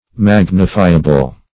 Search Result for " magnifiable" : The Collaborative International Dictionary of English v.0.48: Magnifiable \Mag"ni*fi`a*ble\, a. [From Magnify .] Such as can be magnified, or extolled.
magnifiable.mp3